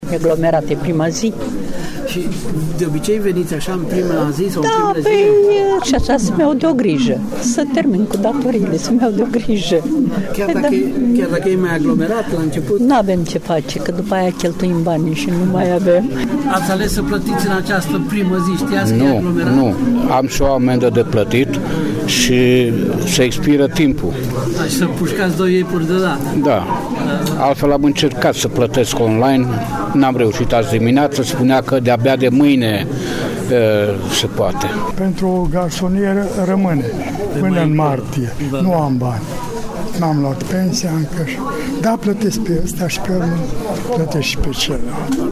voxuri-brasov.mp3